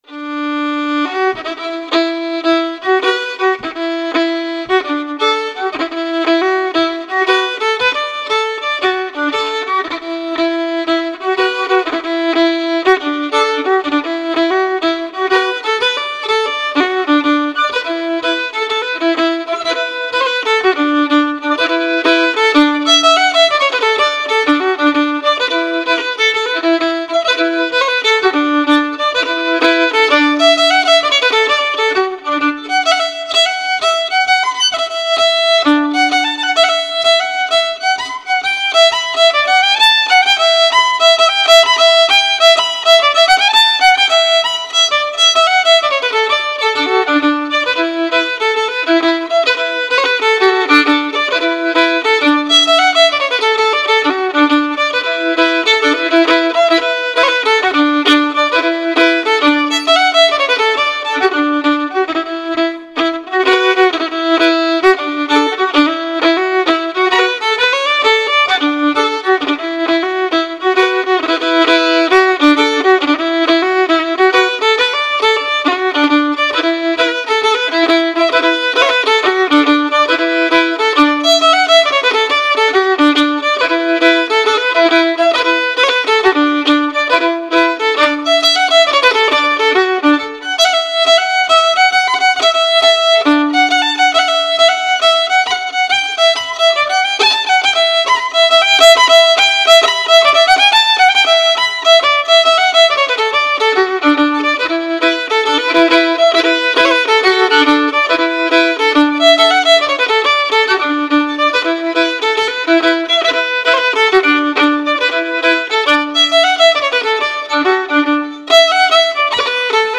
It promotes traditional Cape Breton style music through fiddle, guitar, piano, singers, step dancers, and lovers of Cape Breton Fiddle Music.
E Minor Reels